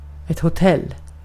Ääntäminen
Ääntäminen : IPA: [hɔ.ˈtɛlː] Haettu sana löytyi näillä lähdekielillä: ruotsi Käännös Ääninäyte Substantiivit 1. hotel US Artikkeli: ett .